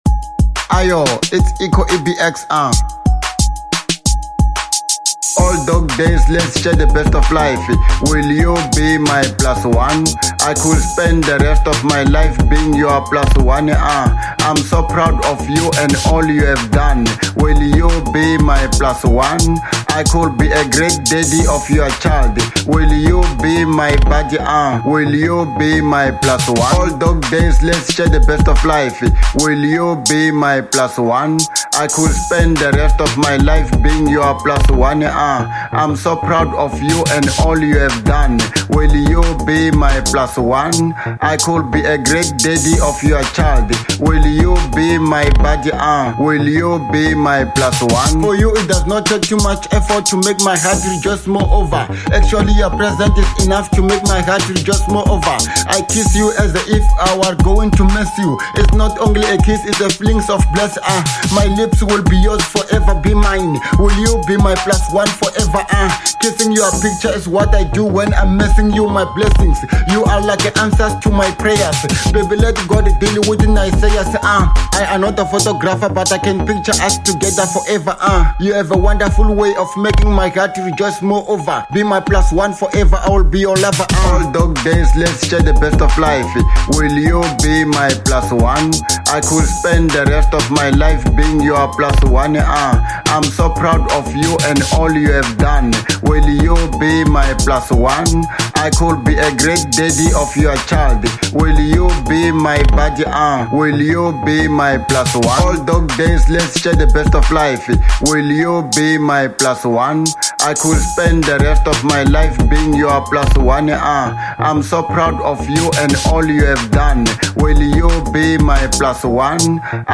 03:42 Genre : Hip Hop Size